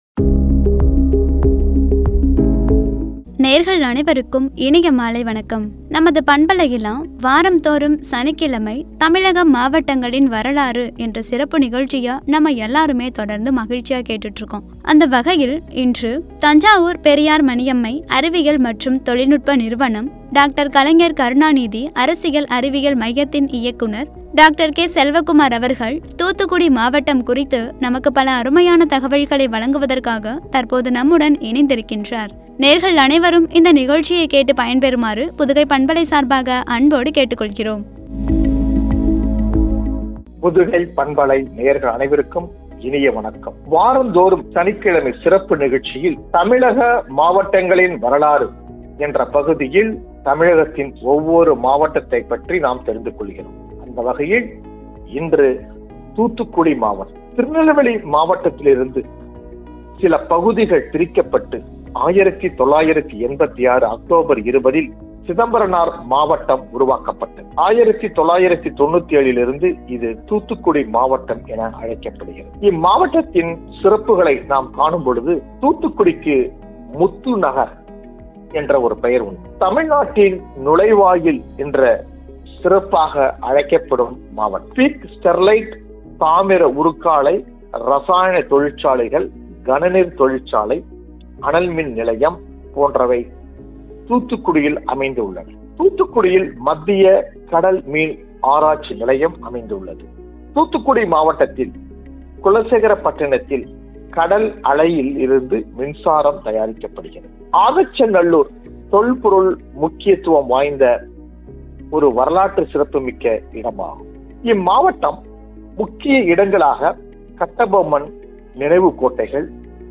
என்ற தலைப்பில் வழங்கிய உரை.